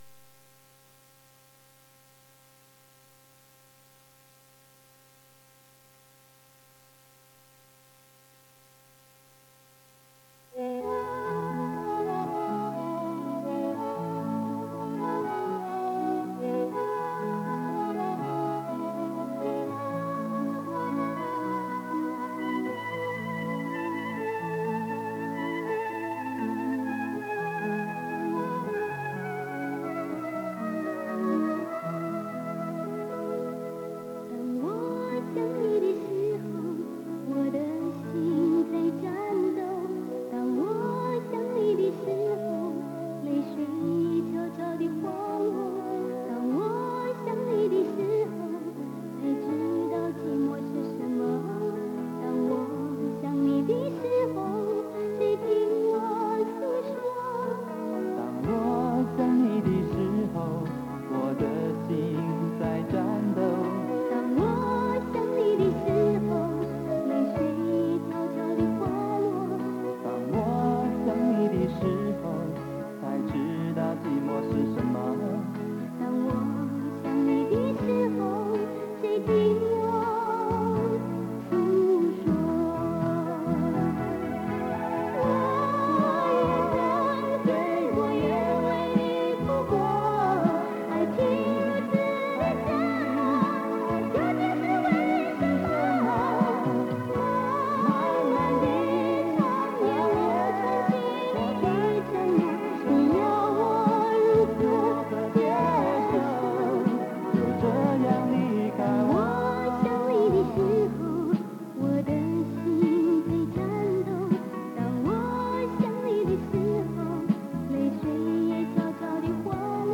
磁带数字化：2022-06-05